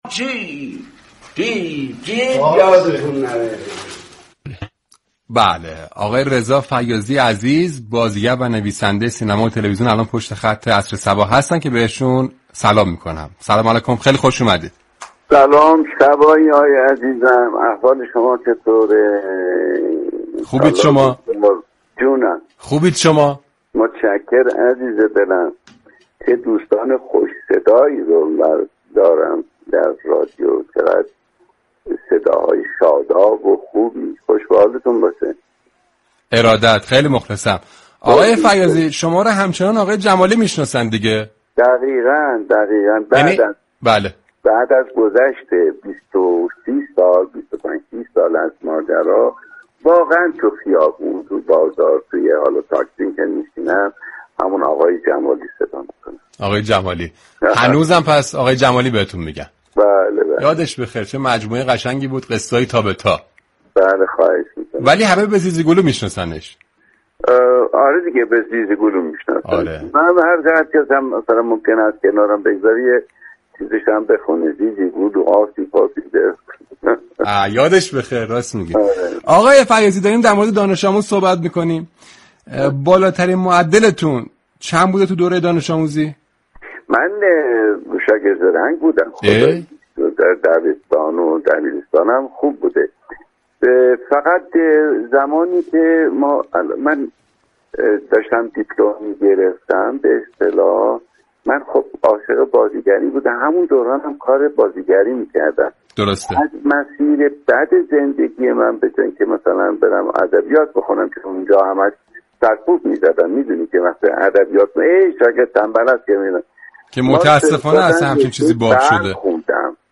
رضا فیاضی بازیگر و نویسنده خوب كشورمان در گفتگو با صباهنگ ازنقش ماندگارش در مجموعه قصه ای تابه تا گفت.